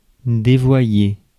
Ääntäminen
IPA : /ˈmɪsliːd/